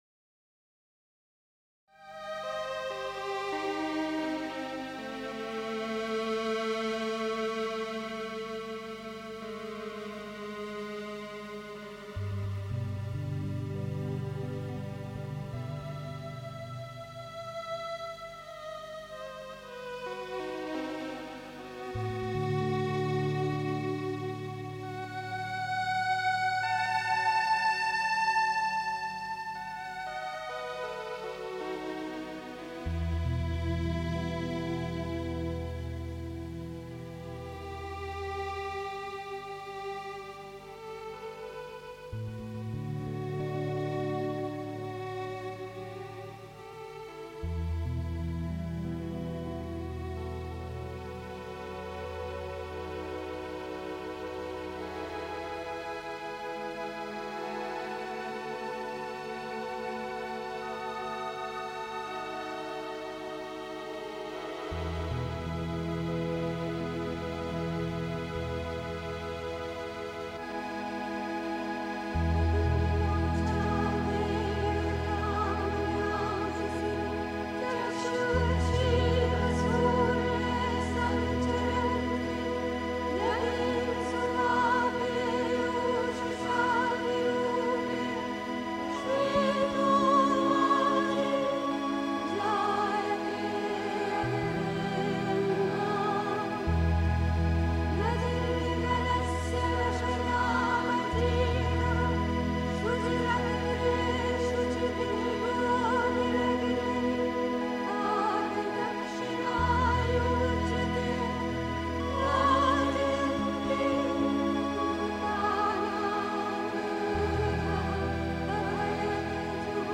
Um das Göttliche wirklich zu lieben (Die Mutter, CWM Vol. 14, p. 131) 3. Zwölf Minuten Stille.